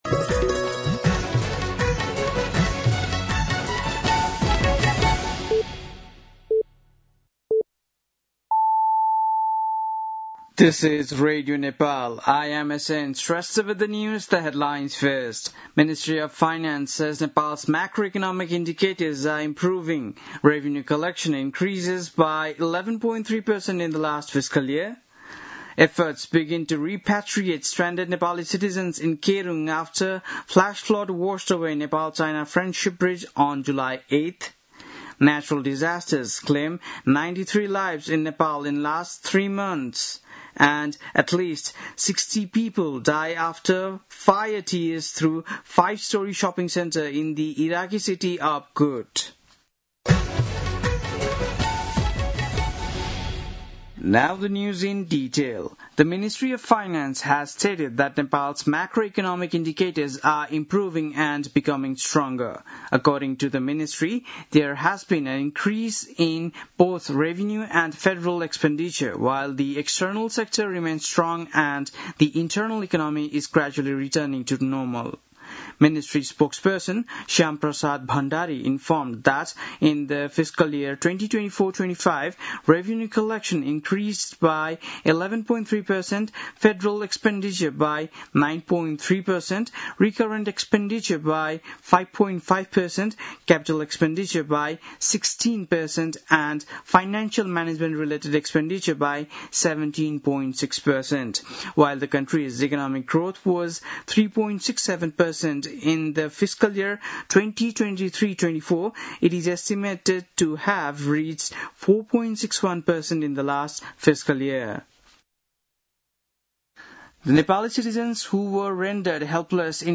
बेलुकी ८ बजेको अङ्ग्रेजी समाचार : १ साउन , २०८२